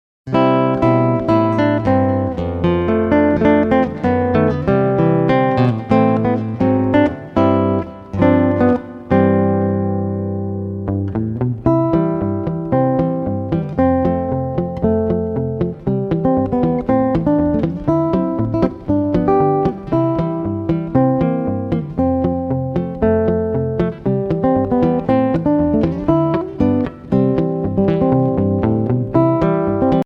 Voicing: Guitar Tab